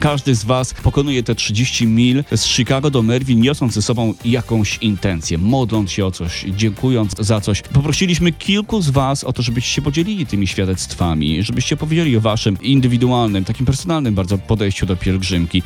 GOŚCIE BUDZIK MORNING SHOW